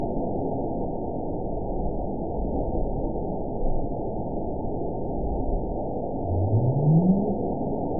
event 922725 date 03/22/25 time 23:05:44 GMT (2 months, 3 weeks ago) score 9.50 location TSS-AB04 detected by nrw target species NRW annotations +NRW Spectrogram: Frequency (kHz) vs. Time (s) audio not available .wav